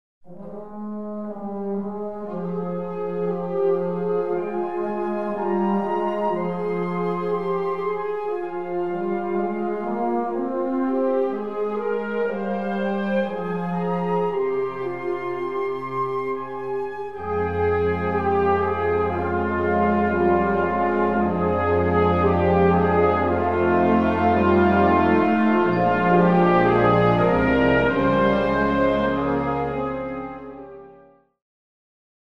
Gattung: Russische Tanzsuite
Besetzung: Blasorchester